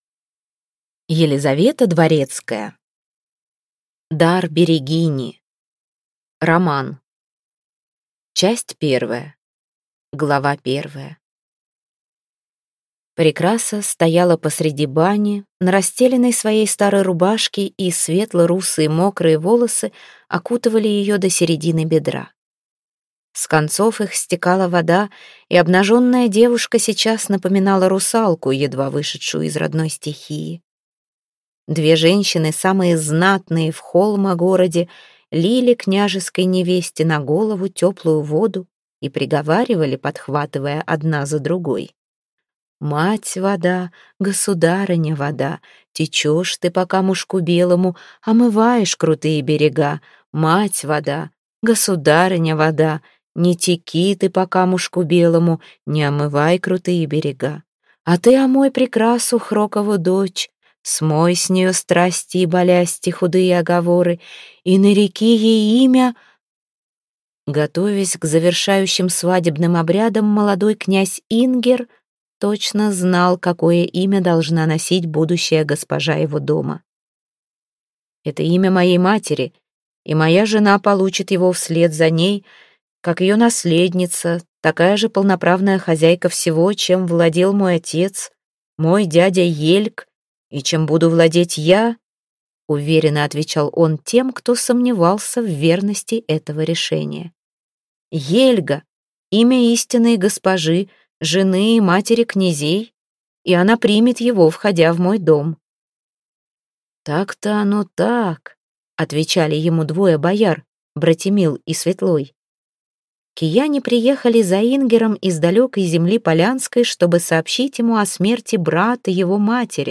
Аудиокнига Дар берегини | Библиотека аудиокниг
Прослушать и бесплатно скачать фрагмент аудиокниги